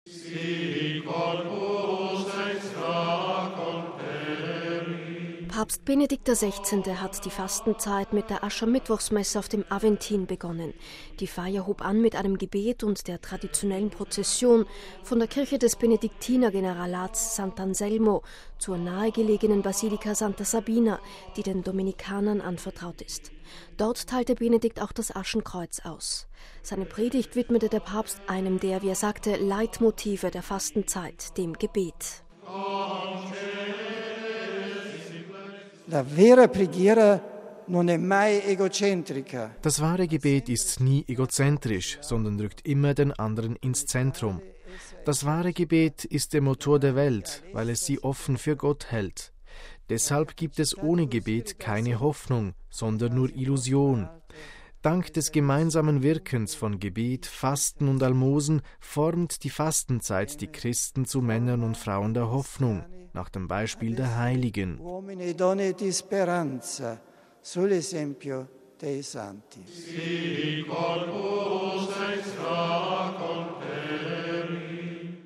Papst Benedikt XVI. widmete seine Predigt beim traditionellen Aschermittwochs-Gottesdienst in der Basilika Santa Sabina auf dem Aventin dem Gebet, einem, wie er es ausdrückte, „Leitmotiv“ der Fastenzeit.